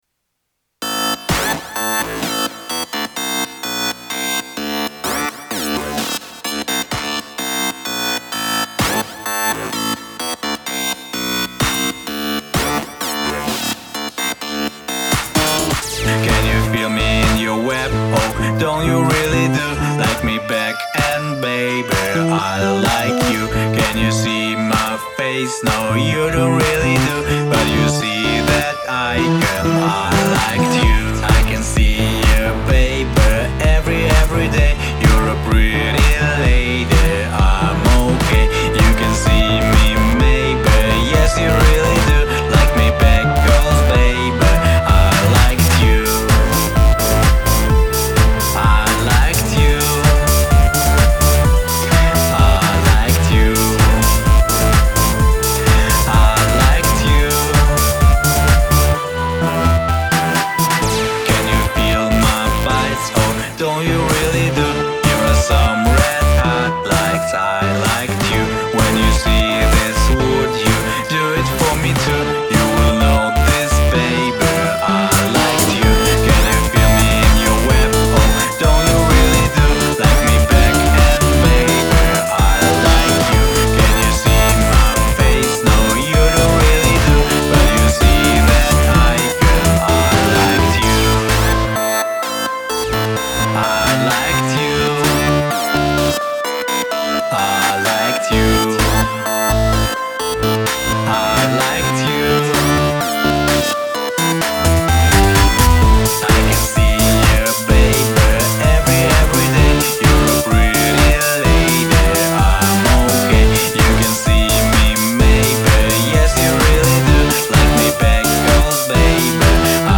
pop
dance
electro
positive